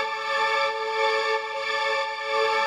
SaS_MovingPad05_90-A.wav